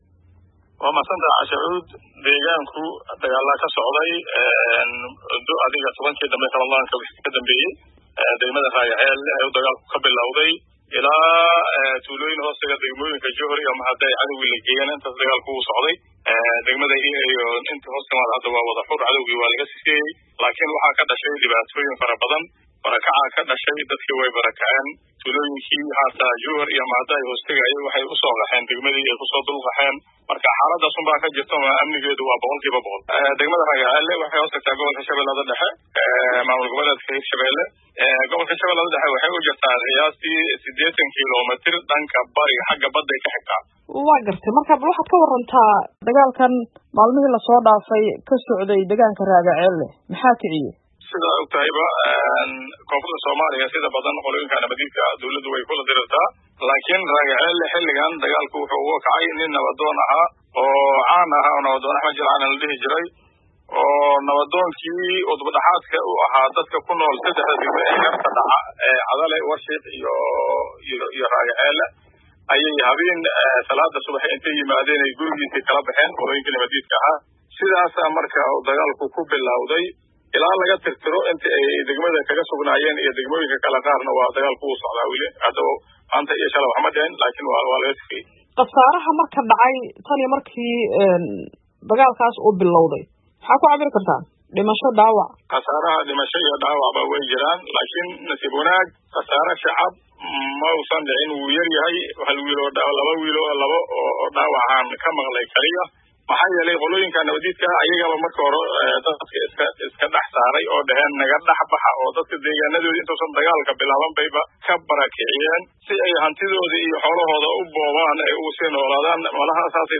xaaladdaas ka waraysatay Cumar Cali Nuur